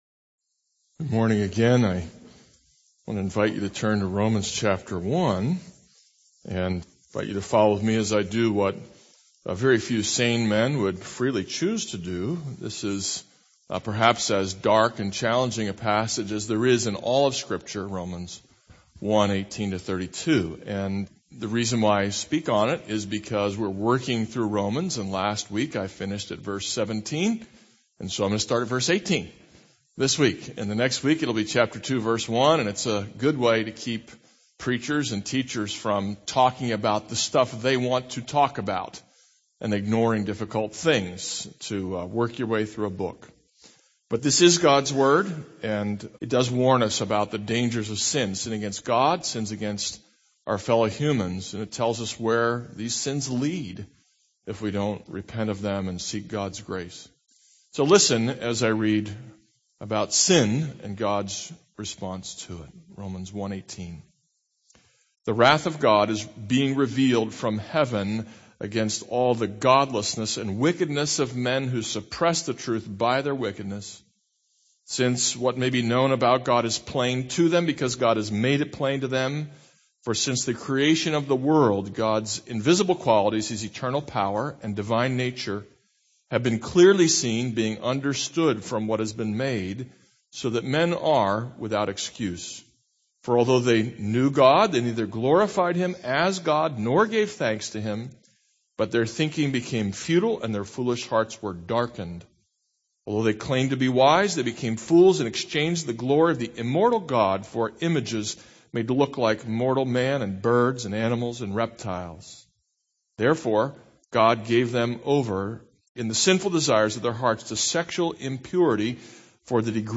This is a sermon on Romans 1:18-32.